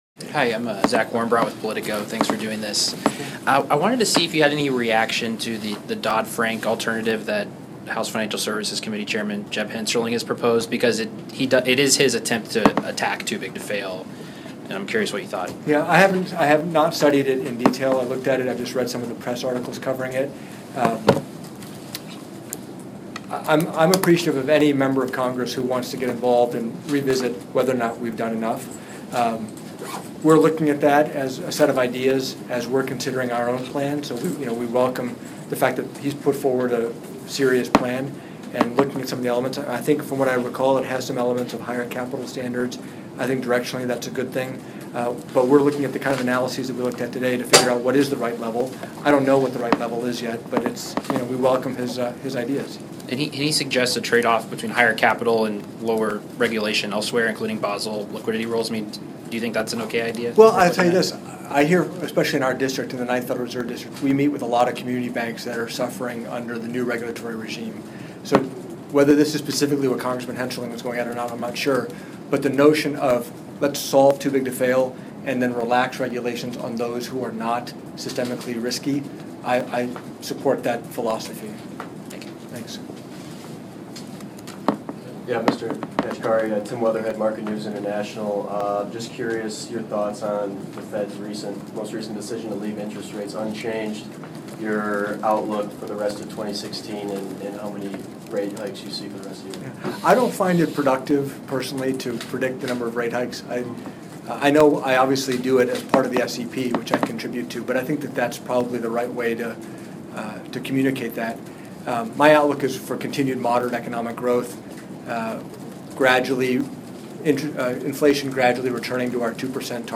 Remarks given at the third symposium on Ending Too Big to Fail at the Peterson Institute in Washington, D.C.